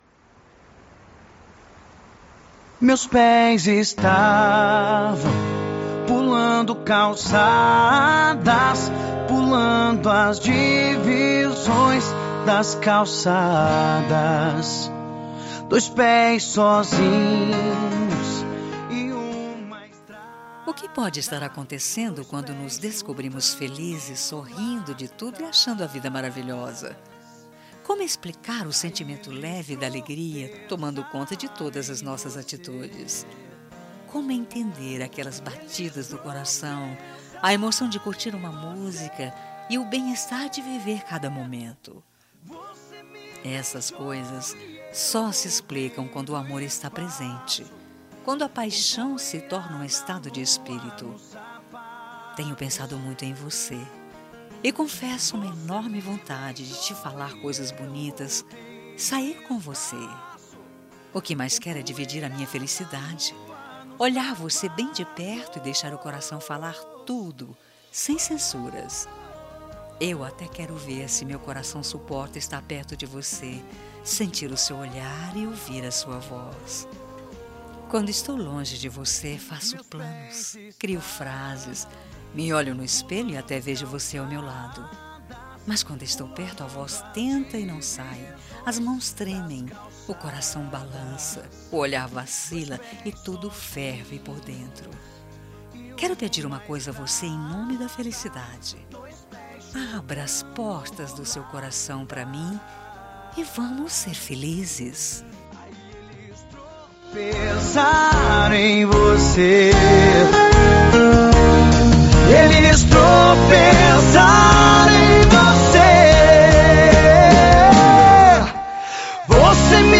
Telemensagem de Conquista – Voz Masculina – Cód: 10545